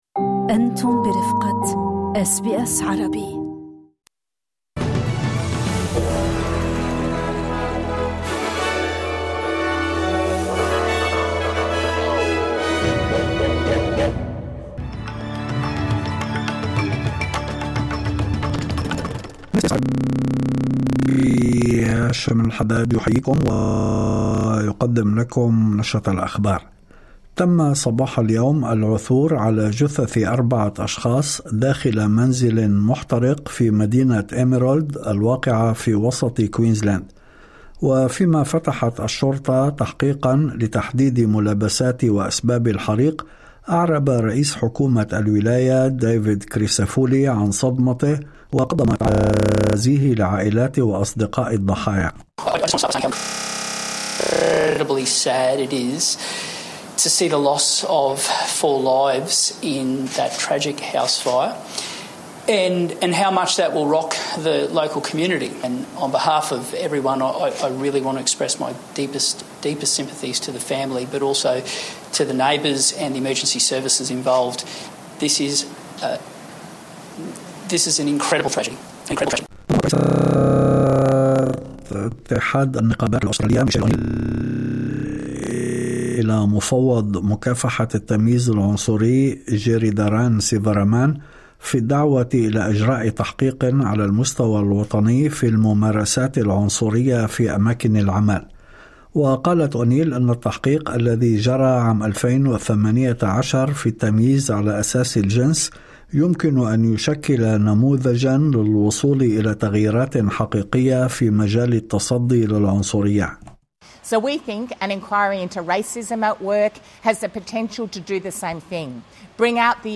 نشرة أخبار الظهيرة 06/11/2025